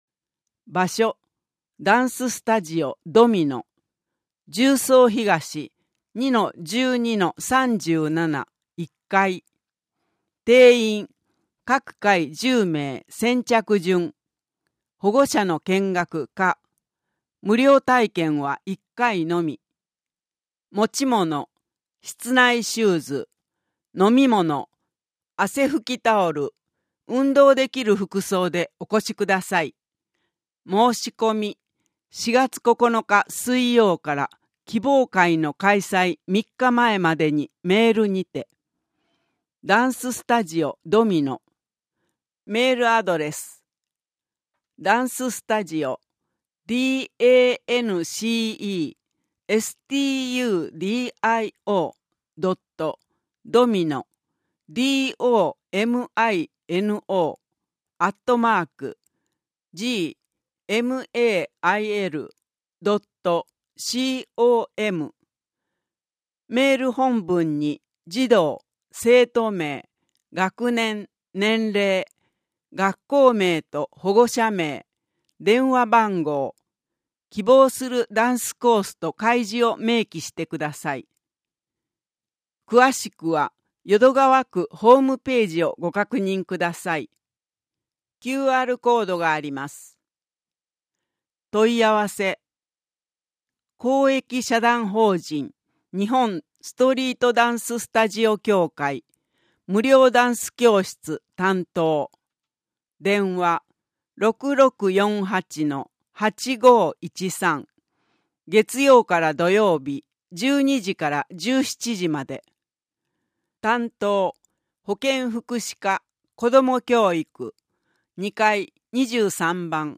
音訳版「よどマガ！」（令和7年4月号）